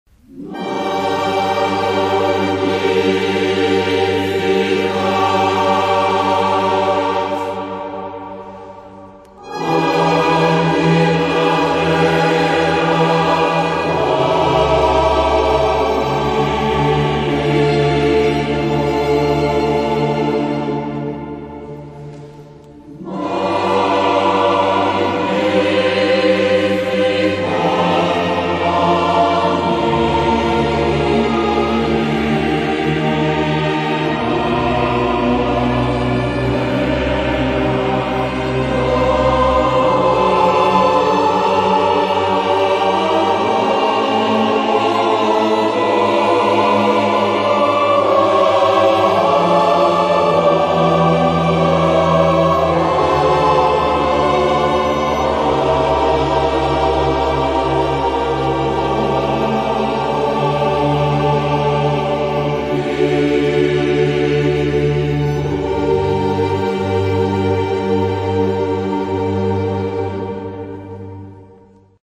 IL CORO dell'AVENTINO di Roma: un coro importante, bellissimo.
Il Coro, composto da circa 70 elementi, si è esibito in numerosi concerti presso le più prestigiose basiliche di Roma ed anche a favore di iniziative di solidarietà.
Magnificat (Vivaldi) (Coro  Aventino).mp3